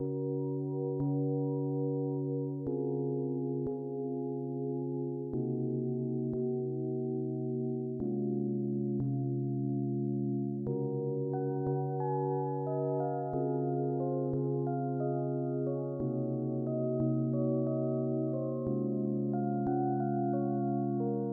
枇杷叶和弦和旋律
描述：悲伤
标签： 90 bpm Hip Hop Loops Piano Loops 3.59 MB wav Key : Unknown
声道立体声